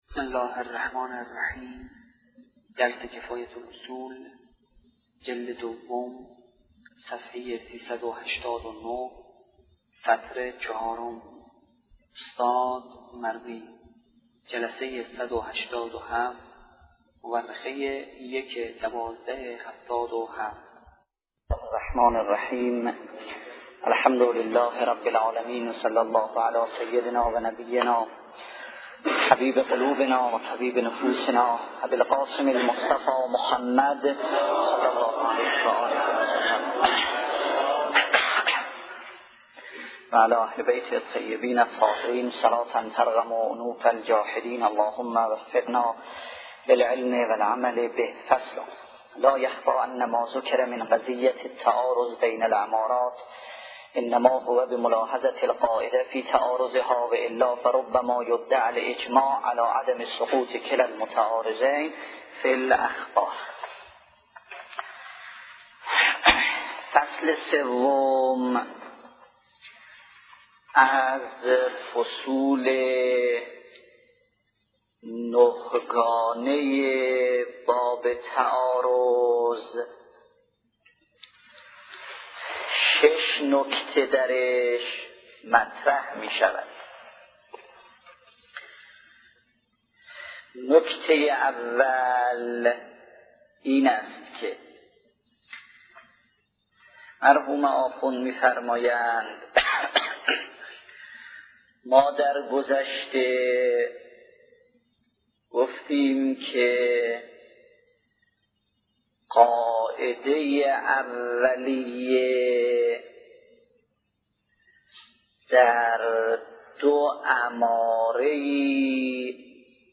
نیوایج